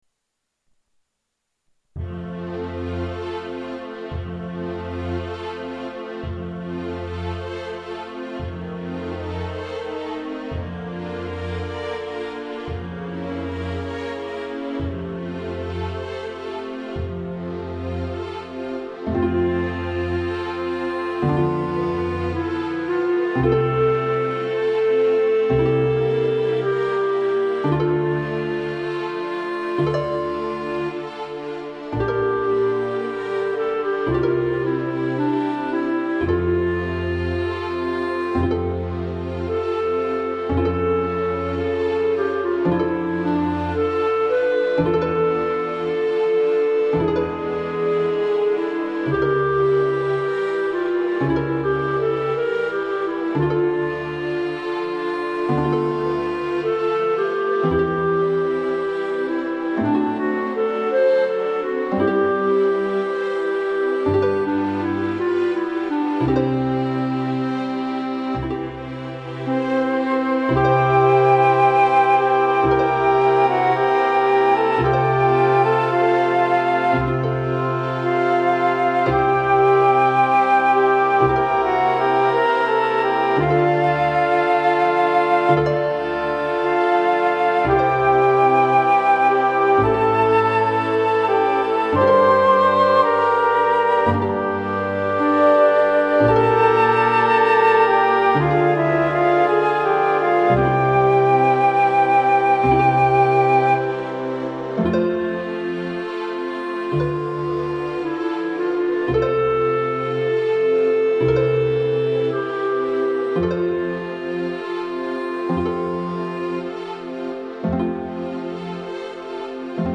Proponiamo l'Ave Maria in versione didattica per flauto.